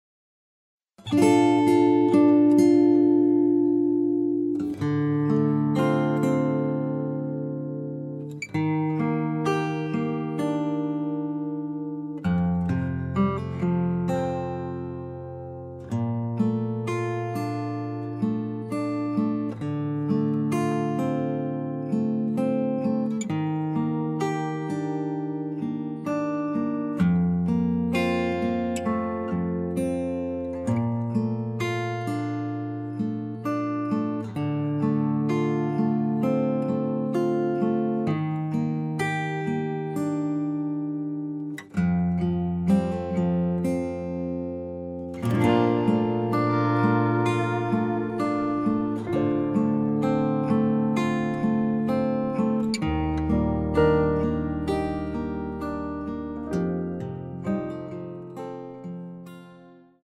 공식 음원 MR
Bb
앞부분30초, 뒷부분30초씩 편집해서 올려 드리고 있습니다.
중간에 음이 끈어지고 다시 나오는 이유는